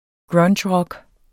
Udtale [ ˈgɹʌndɕˌʁʌg ]